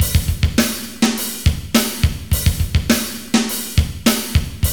ROCK BEAT 2.wav